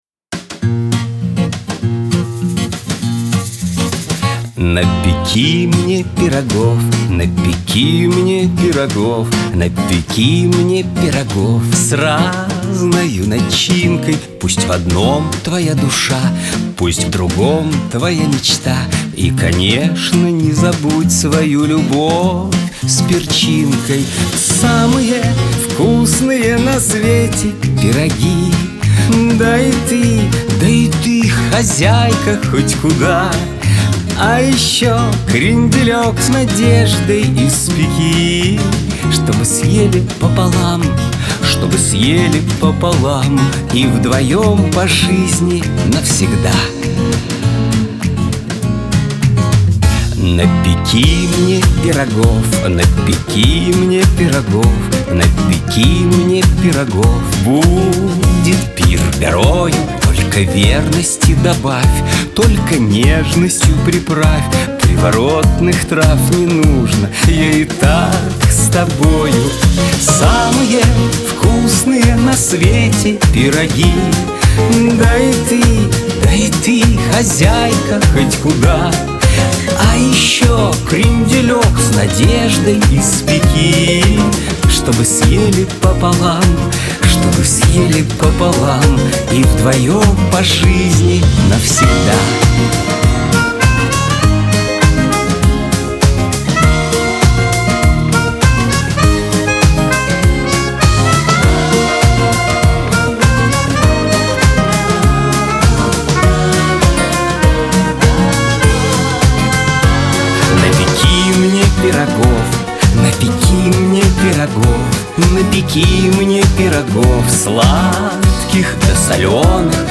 Веселая музыка